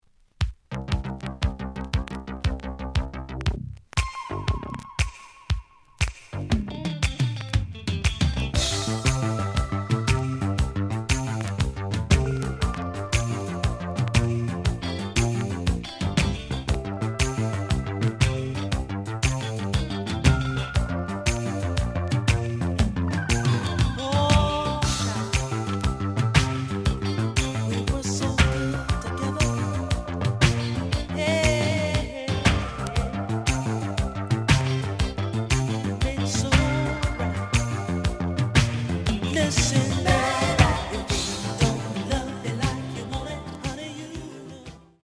The audio sample is recorded from the actual item.
●Genre: Soul, 80's / 90's Soul